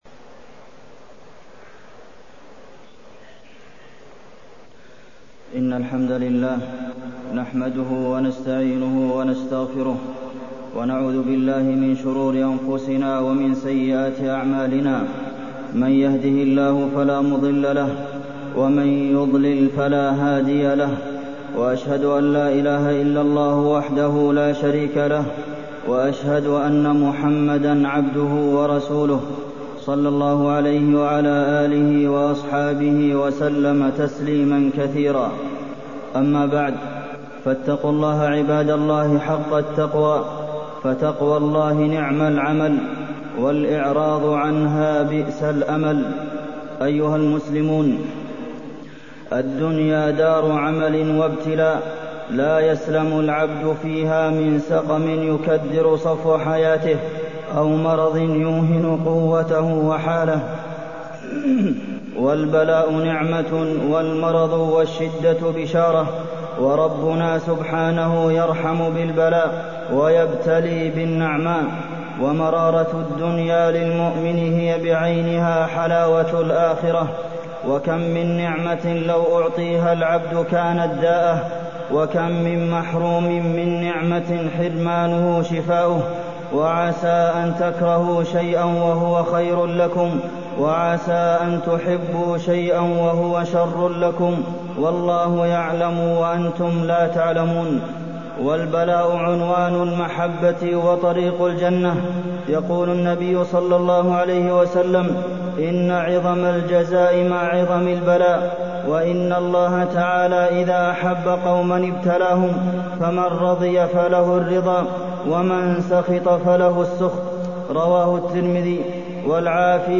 تاريخ النشر ١٠ ربيع الثاني ١٤٢٣ المكان: المسجد النبوي الشيخ: فضيلة الشيخ د. عبدالمحسن بن محمد القاسم فضيلة الشيخ د. عبدالمحسن بن محمد القاسم سلوان المرضى